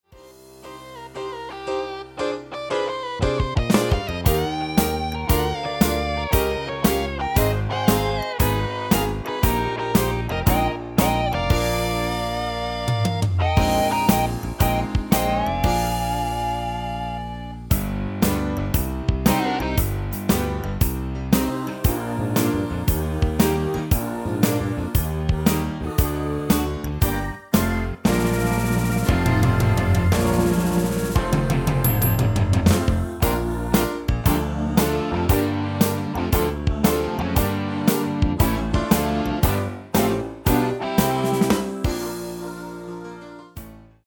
MP3-orkestband Euro 5.75